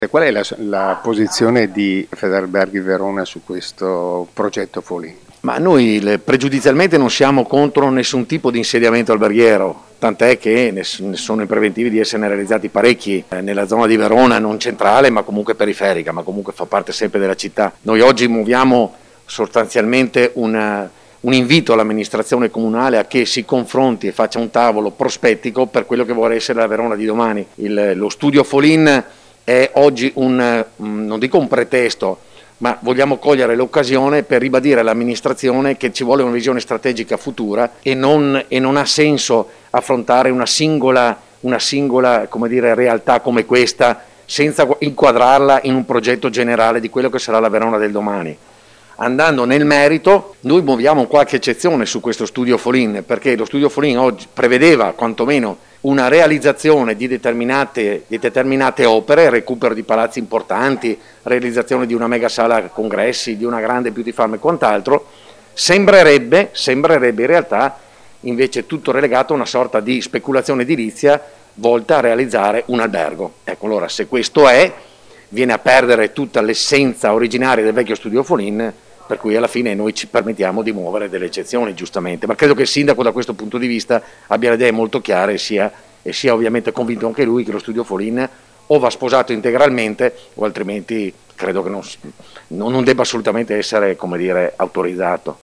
Le interviste